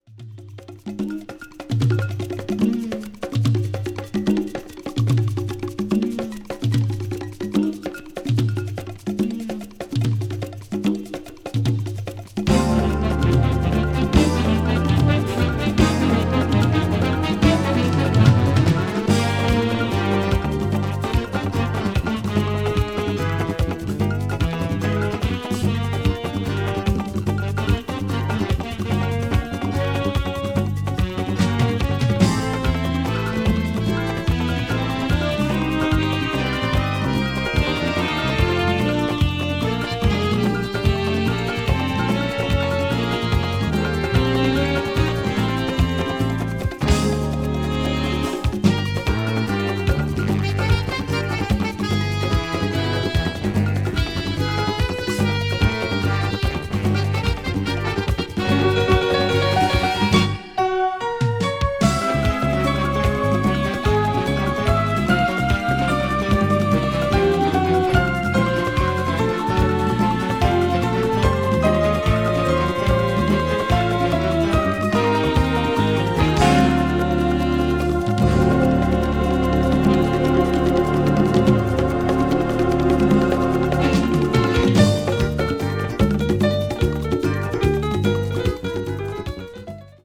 brazil   contemporary jazz   minus   world music